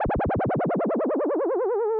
Reverse Sine Modulated.wav